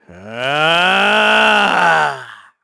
KaselB-Vox_Casting3_kr.wav